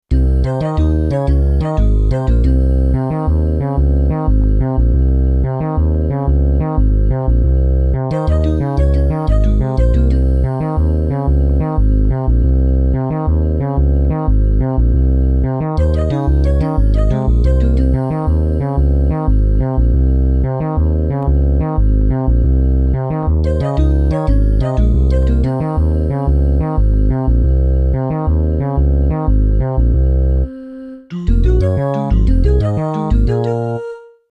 Fmin bass lick
Fminbasslick.mp3